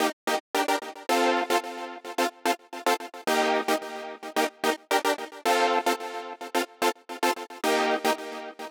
30 Synth PT1.wav